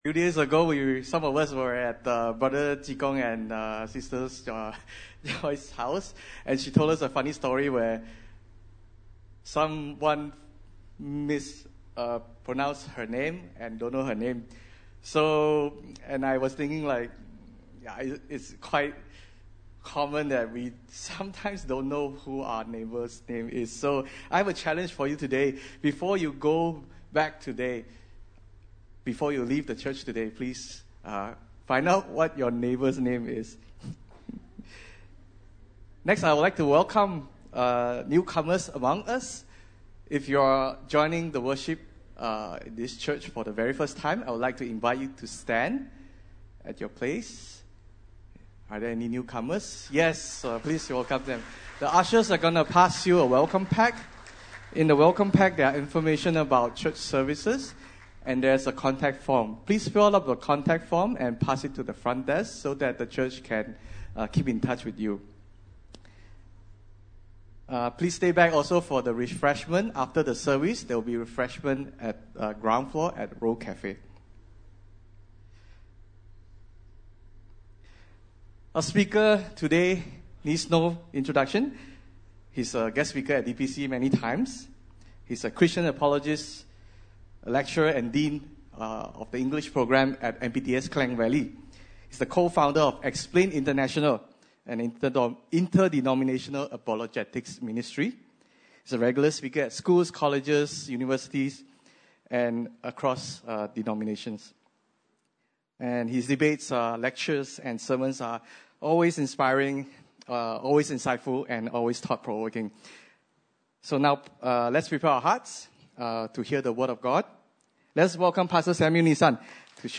Passage: Luke 16:19-31 Service Type: Sunday Service (Desa ParkCity) « Marriage